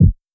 Kicks
SB6 Kicc (4).wav